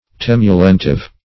Search Result for " temulentive" : The Collaborative International Dictionary of English v.0.48: Temulentive \Tem"u*lent*ive\, a. Somewhat temulent; addicted to drink.
temulentive.mp3